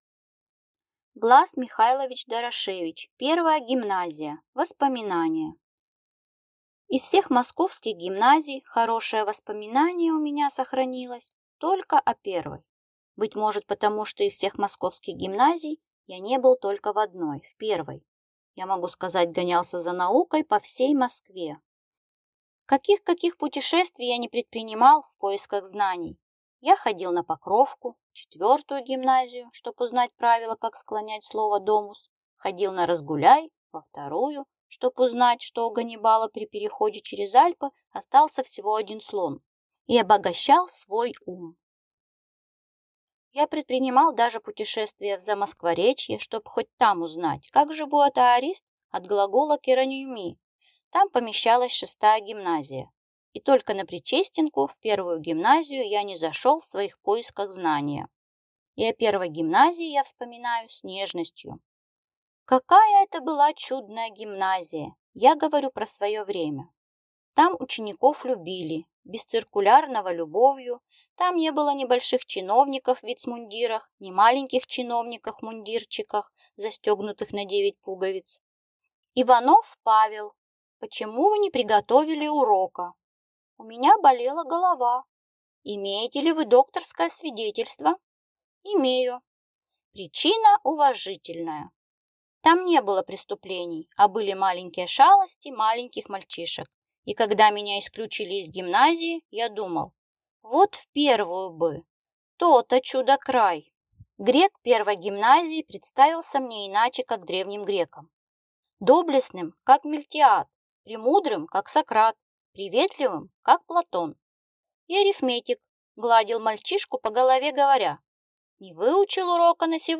Аудиокнига Первая гимназия | Библиотека аудиокниг